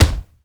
punch_general_body_impact_07.wav